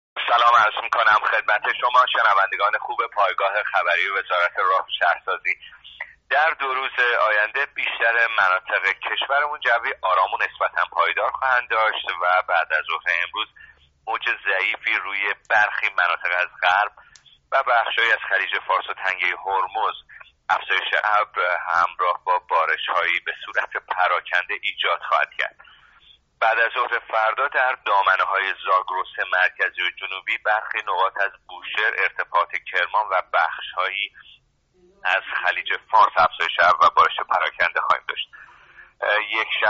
کارشناس سازمان هواشناسی در گفت‌و‌گو با راديو اينترنتی پايگاه خبری آخرين وضعيت هوا را تشریح کرد.